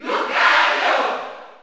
Category: Crowd cheers (SSBU) You cannot overwrite this file.
Lucario_Cheer_German_SSBU.ogg.mp3